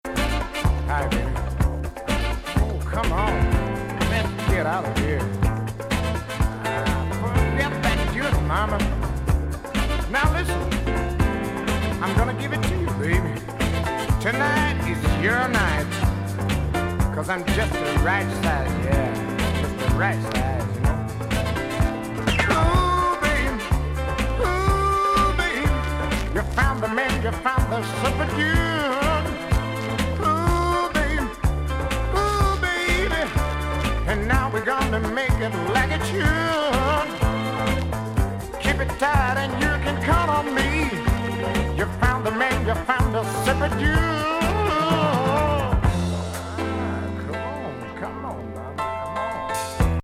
フランス産マイナー・ディスコ78年作。
声ボーカルのソウルフル・グルーヴ・ブギー